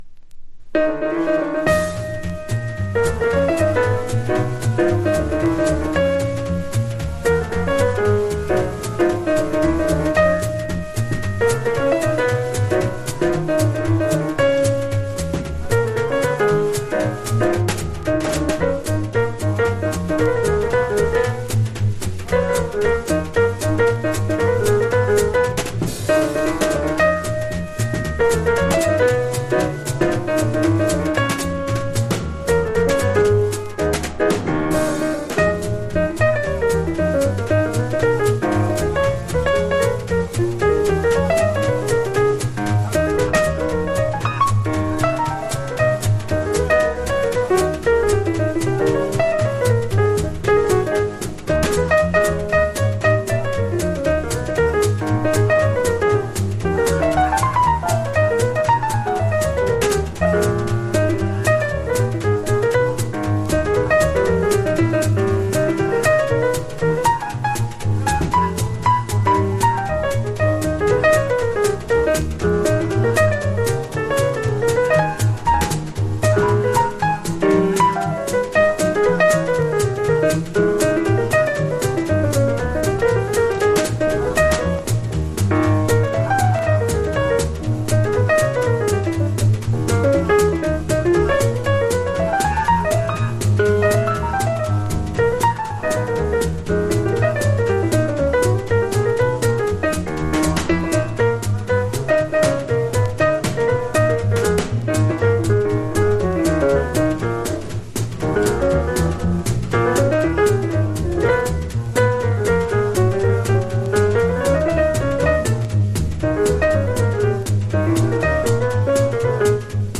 トリオもの名盤